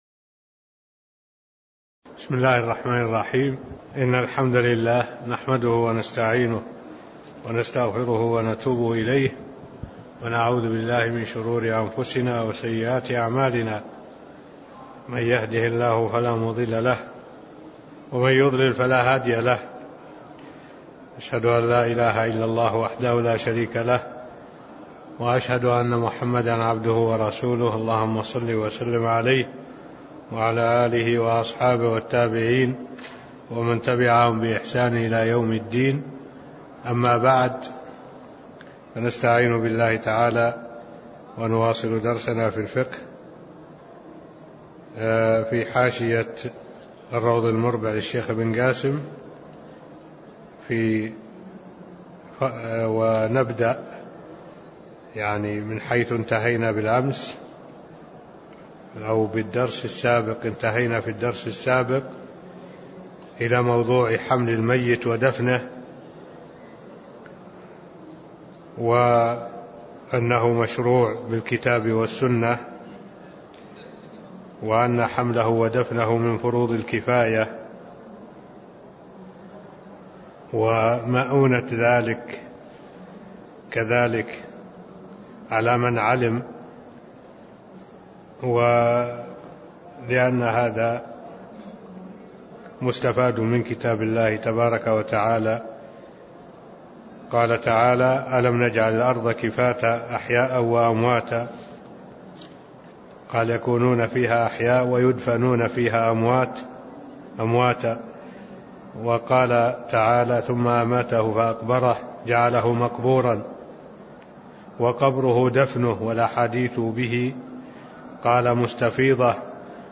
تاريخ النشر ١٦ صفر ١٤٢٩ هـ المكان: المسجد النبوي الشيخ: معالي الشيخ الدكتور صالح بن عبد الله العبود معالي الشيخ الدكتور صالح بن عبد الله العبود حمل الميت ودفنه (007)